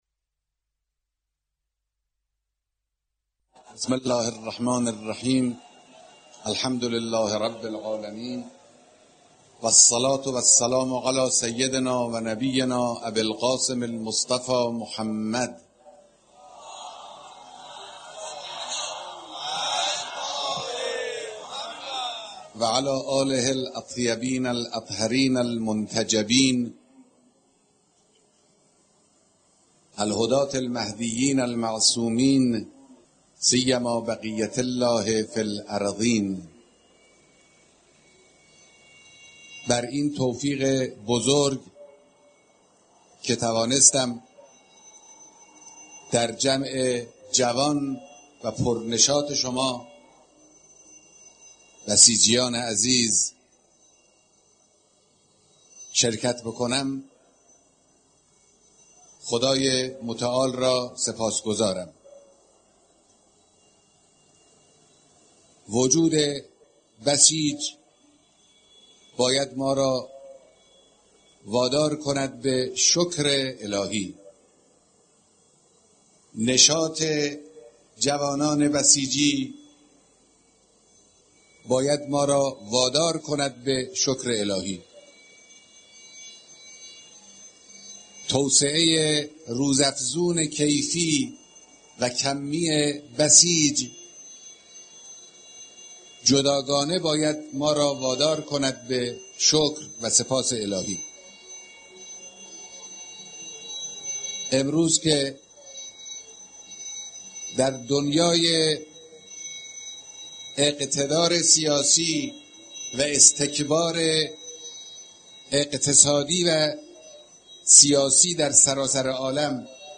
در جمع بسیجیان حاضر در اردوی فرهنگی رزمیِ یاران امام علی(ع)